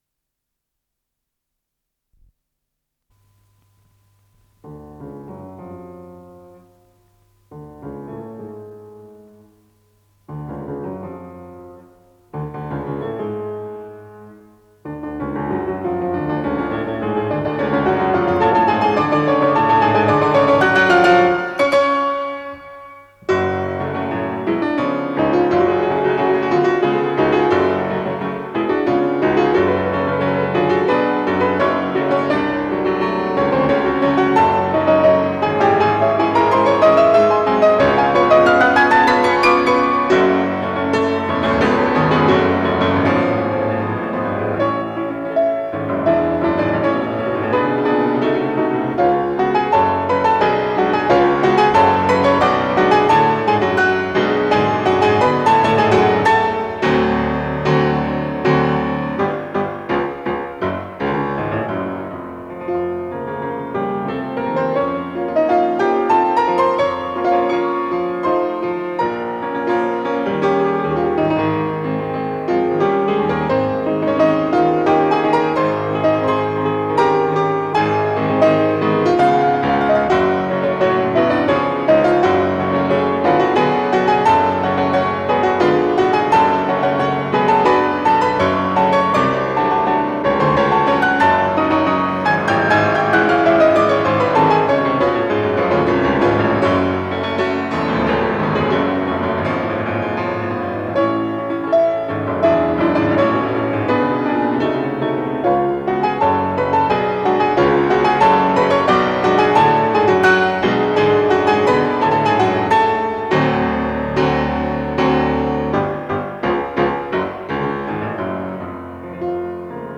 фортепиано
Для фортепиано, фа диез минор